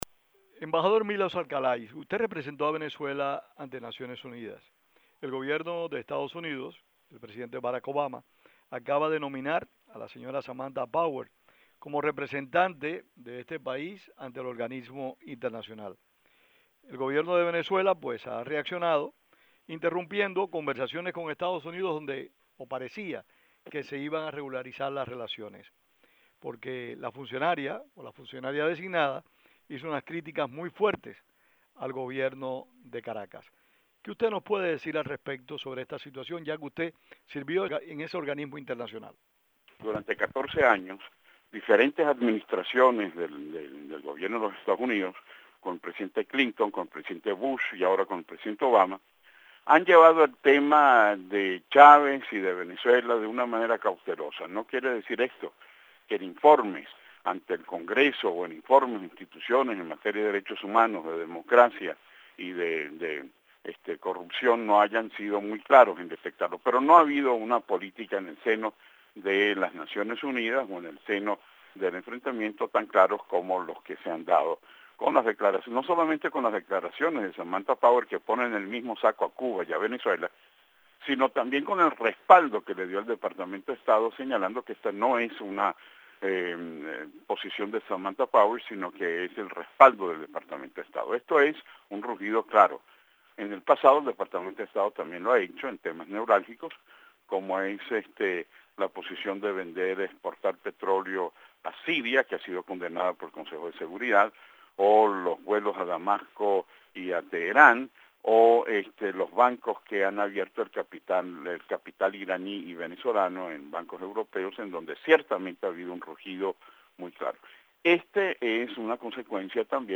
Declaraciones del ex embajador de Venezuela ante Naciones Unidas Milos Alcalay